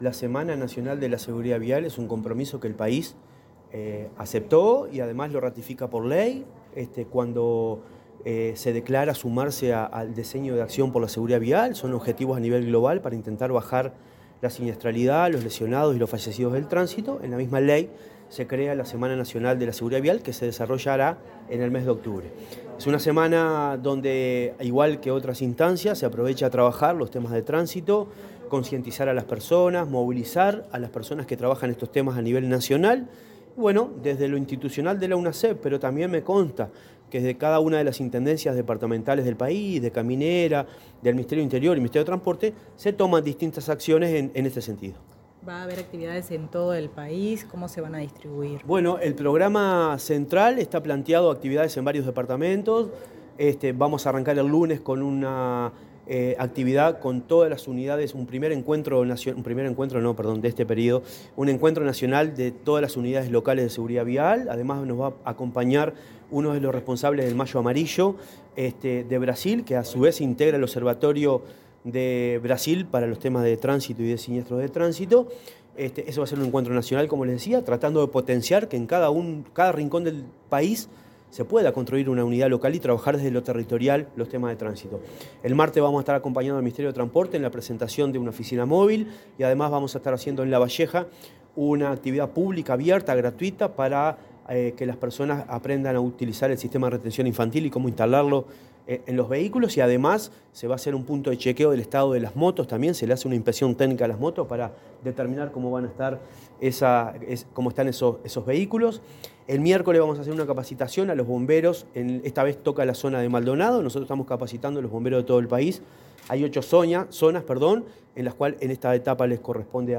Declaraciones del presidente de la Unasev, Marcelo Metediera
Declaraciones del presidente de la Unasev, Marcelo Metediera 10/10/2025 Compartir Facebook X Copiar enlace WhatsApp LinkedIn En oportunidad del lanzamiento de la XVIII Semana Nacional de la Seguridad Vial, el presidente de la Unidad Nacional de Seguridad Vial (Unasev), Marcelo Metediera, informó acerca de las actividades que se llevarán adelante en todo el país.